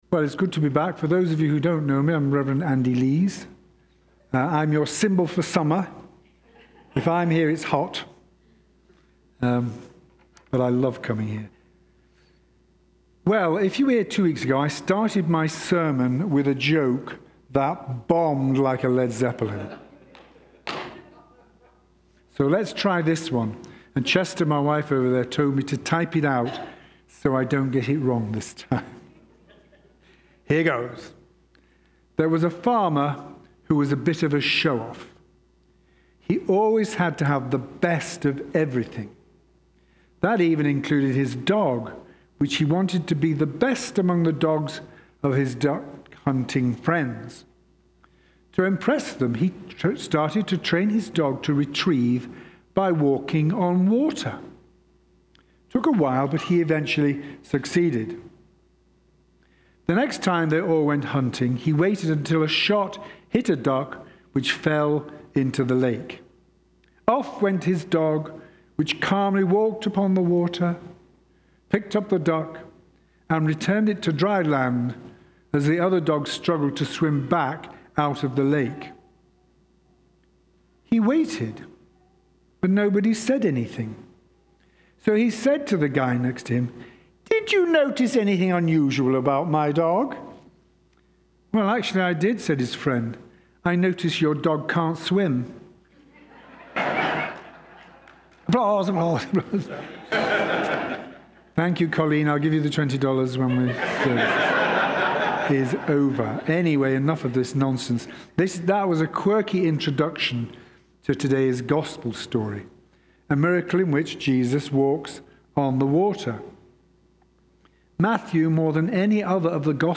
1. Holy Trinity Anglican Church (Calgary)
Sermons